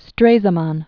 (strāzə-män, shtrā-), Gustav 1878-1929.